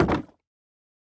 Minecraft Version Minecraft Version snapshot Latest Release | Latest Snapshot snapshot / assets / minecraft / sounds / block / bamboo_wood_fence / toggle4.ogg Compare With Compare With Latest Release | Latest Snapshot
toggle4.ogg